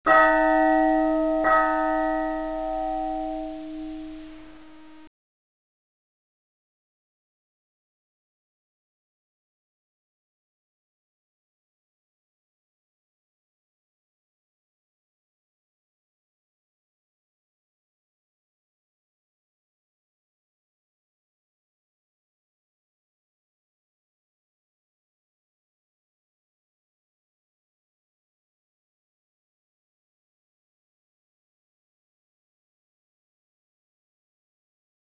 Index of /cs/mapsmirror/sound/ambience
elite_chapel.wav